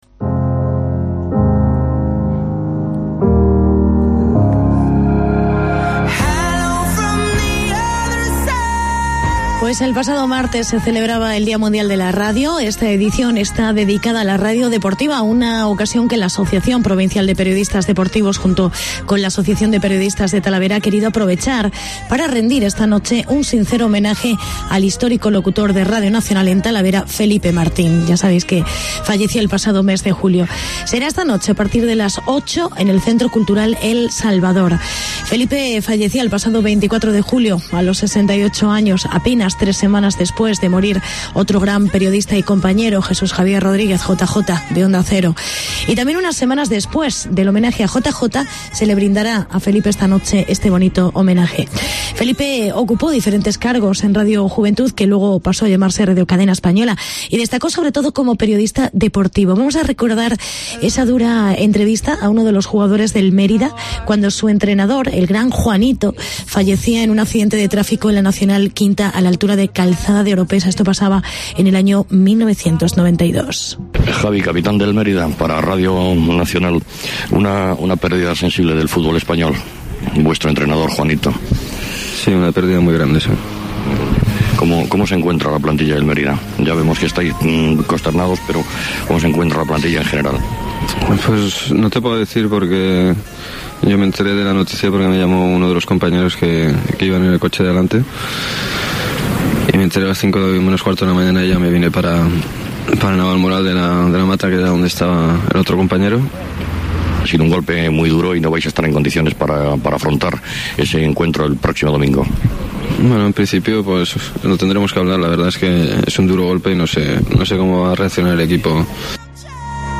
Recordamos algunas de sus entrevistas